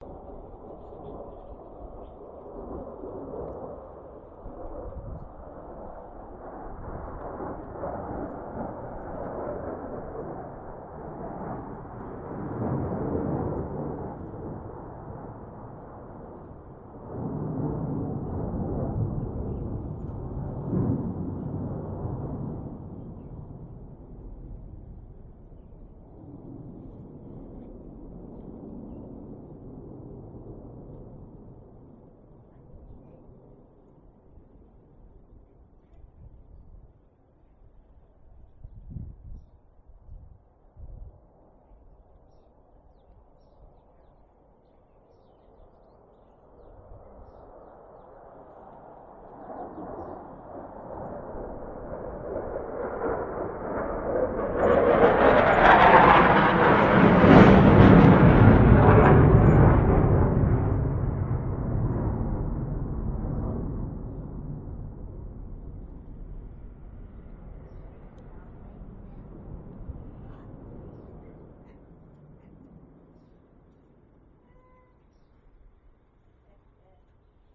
Cubeatz型马林巴舞曲循环2
描述：马林巴琴和竖琴，循环的第二部分
Tag: 132 bpm Trap Loops Bells Loops 2.45 MB wav Key : Unknown FL Studio